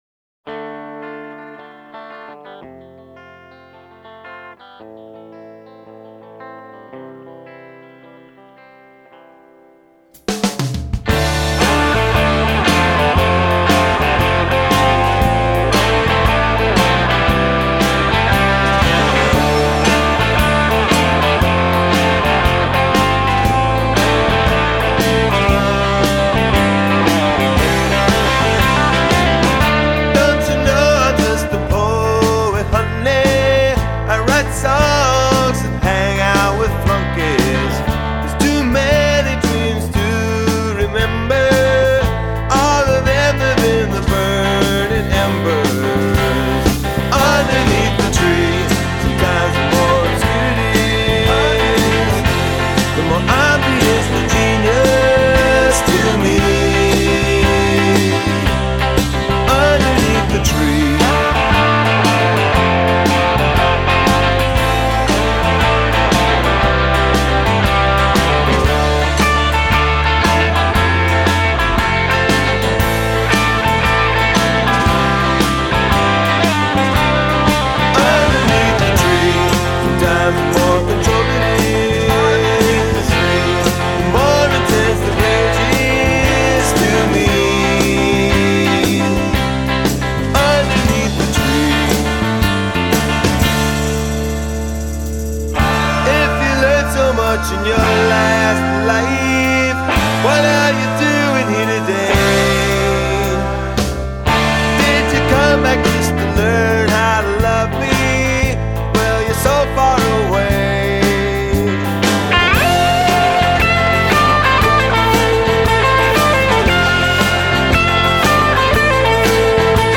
vocals, guitars, harmonica, percussion
bass, vocals, piano, Rhodes piano
drums and percussion
banjo